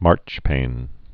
(märchpān)